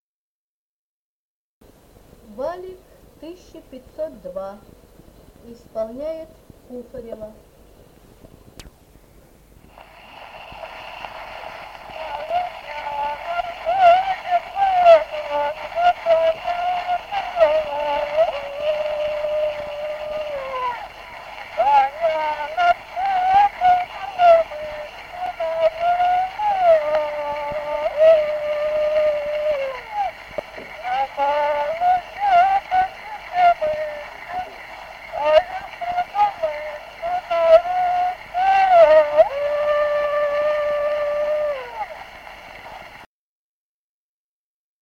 Народные песни Стародубского района «Весна, весняночка», весняная девичья.